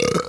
burp.wav